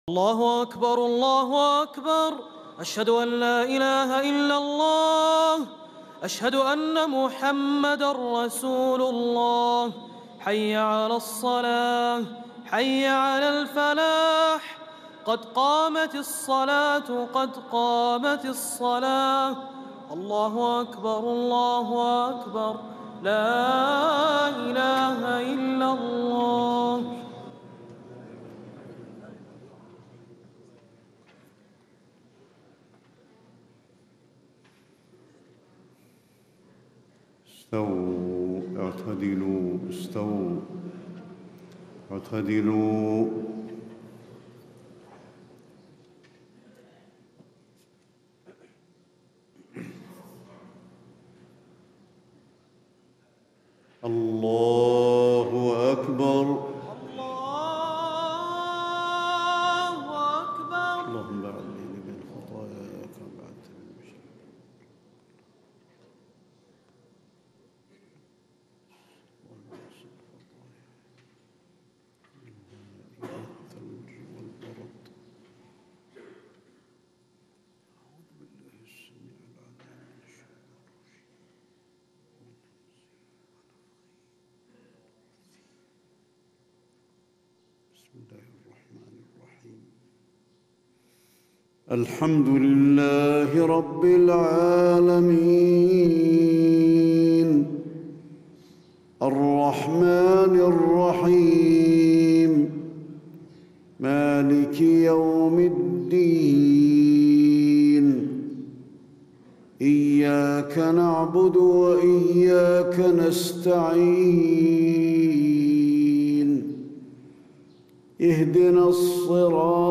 صلاة الفجر 1جمادى الأولى 1437هـ من سورة مريم 67-98 > 1437 🕌 > الفروض - تلاوات الحرمين